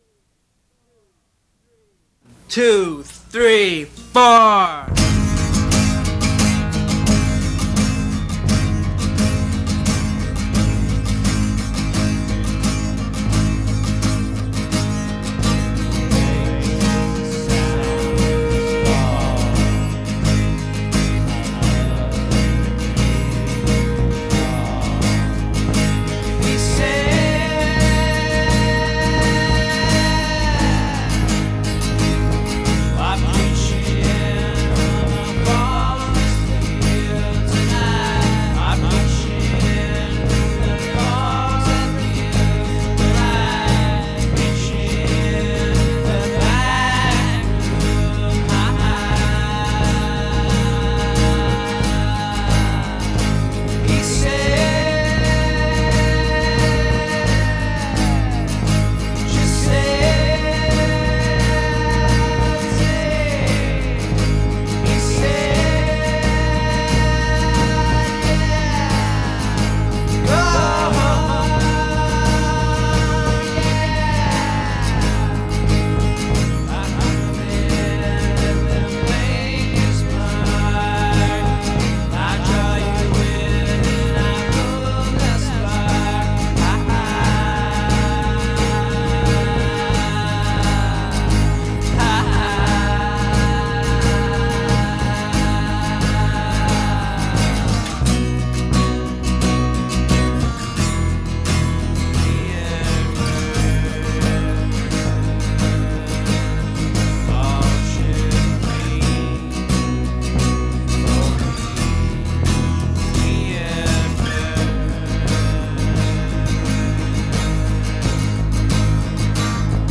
Enjoy the low-fi songs of yearning.